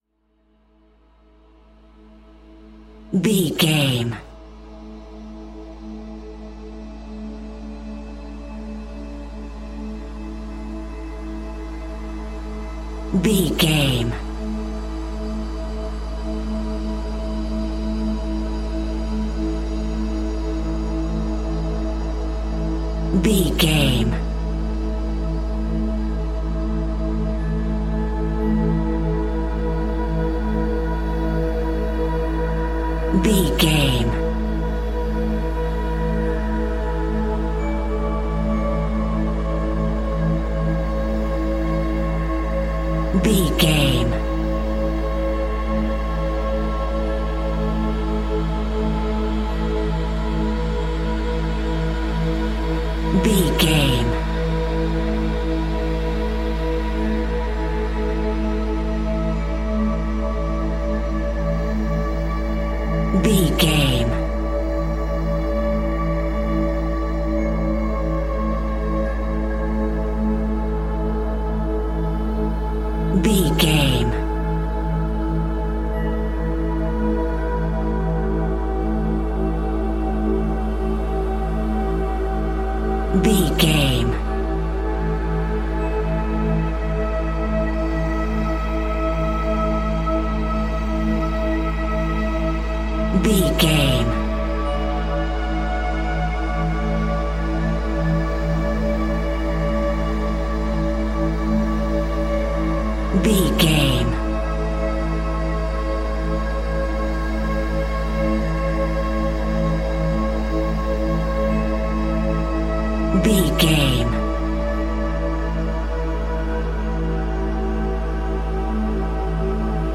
Ionian/Major
Slow
calm
ambient
ethereal
cinematic
meditative
melancholic
dreamy
synthesiser
piano